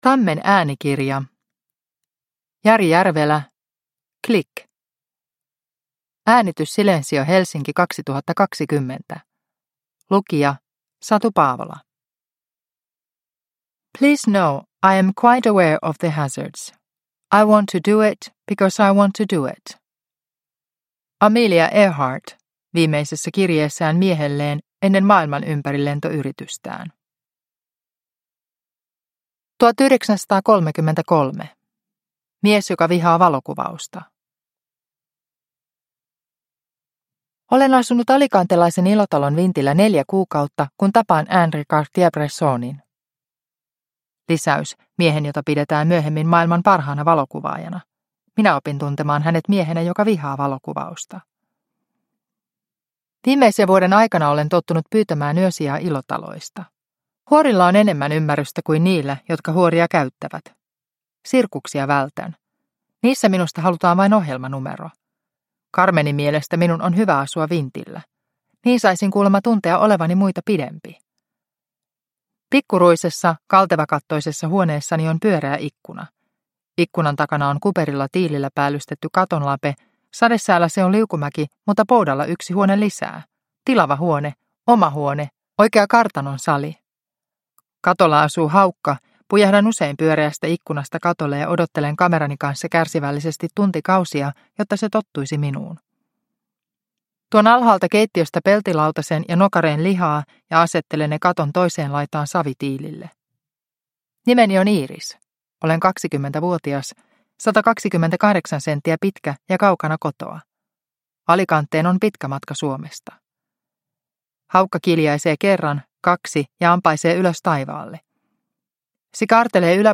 Klik – Ljudbok – Laddas ner